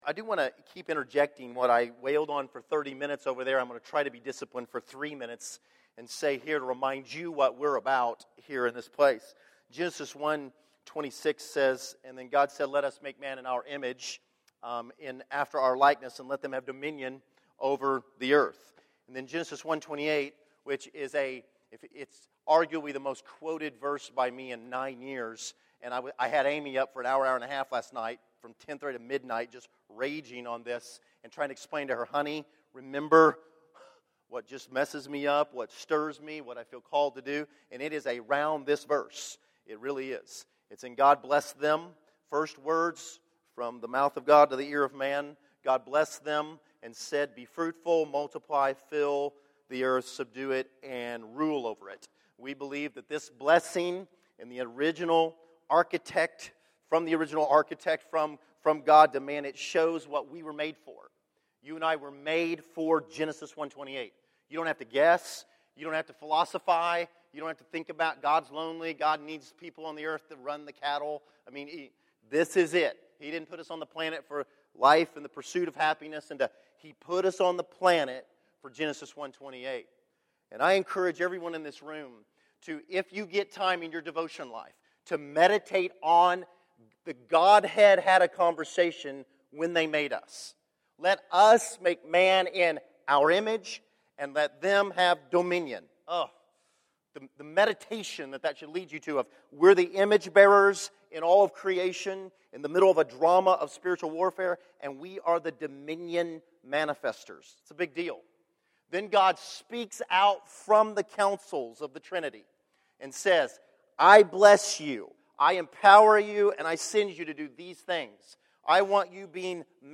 2013 Category: Sermons